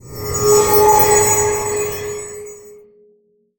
light_in_dark_spell_01.wav